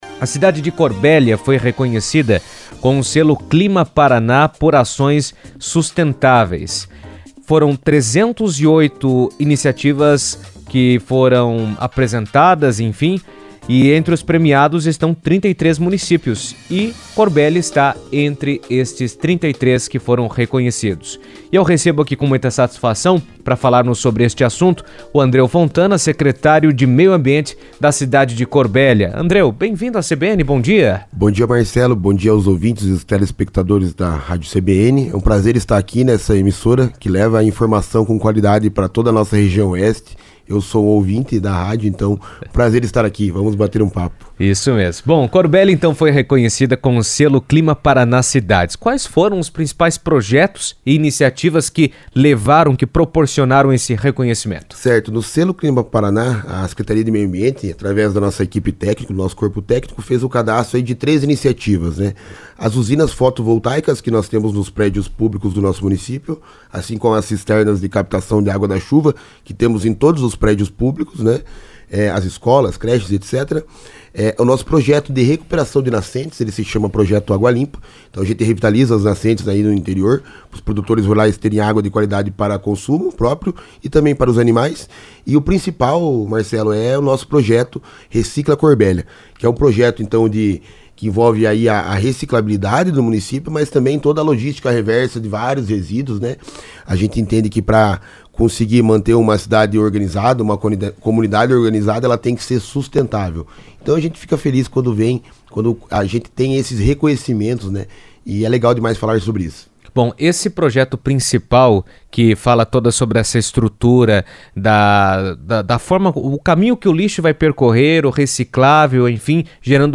Segundo Andreo Fontana, secretário de Meio Ambiente de Corbélia, em entrevista à CBN, a conquista reflete ações consistentes de preservação ambiental e desenvolvimento sustentável no município.